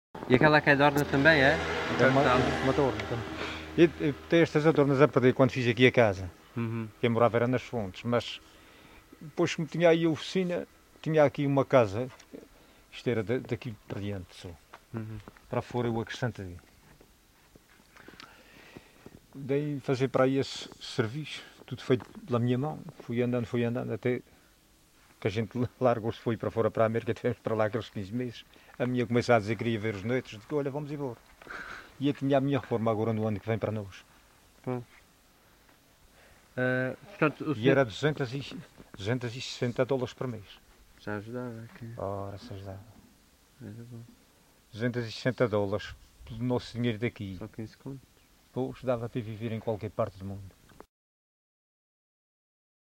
LocalidadeCovas (Santa Cruz da Graciosa, Angra do Heroísmo)